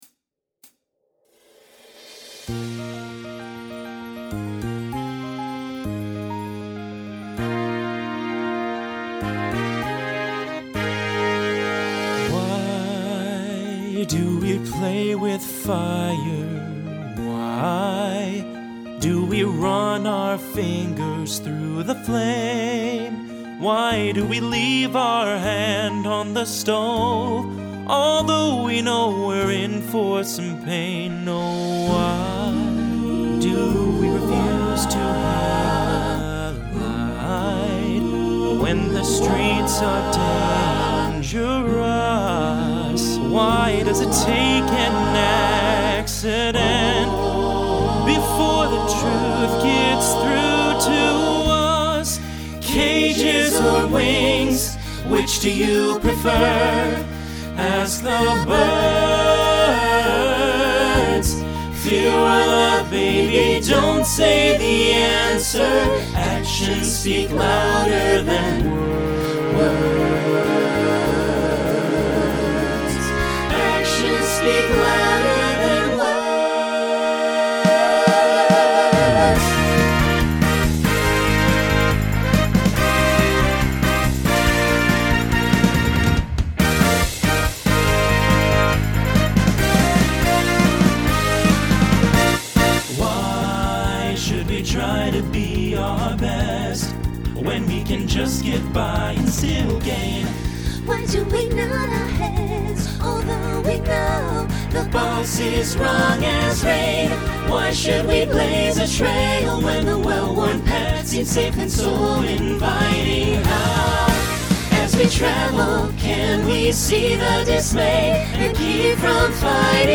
Genre Broadway/Film Instrumental combo
Show Function Opener Voicing SATB